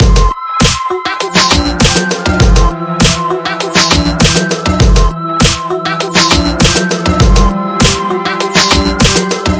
Triphop /舞蹈/拍/嘻哈/毛刺跳/缓拍/寒意
Tag: 寒意 旅行 电子 舞蹈 looppack 样品 毛刺 节奏 节拍 低音 实验 器乐